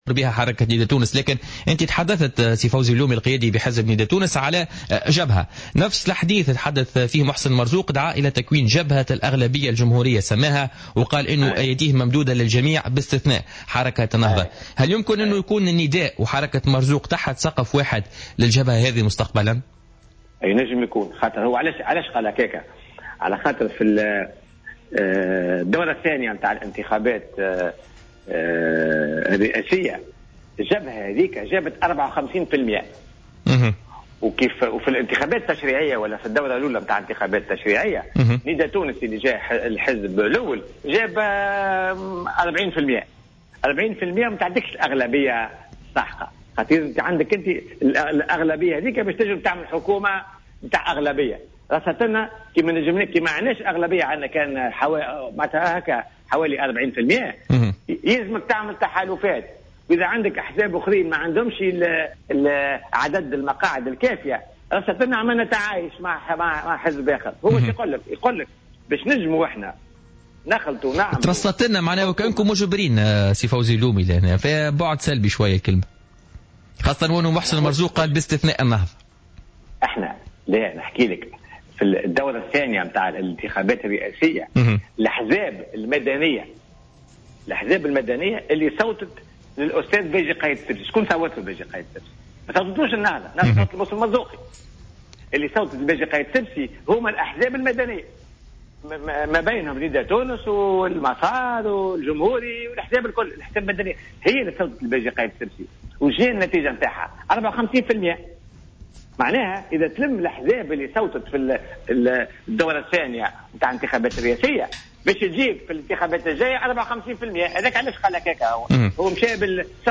وأوضح لـ"الجوهرة أف أم" في برنامج "بوليتيكا"، أن هذه الفرضية غير مستبعدة بحكم القانون الانتخابي الحالي الذي لا يؤدي إلى قيام حكم بأغلبية مريحة يشكلها حزب واحد.